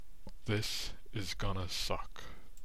描述：Deep male voice saying Chirp.
标签： voice human deep speech chirp vocal request male
声道立体声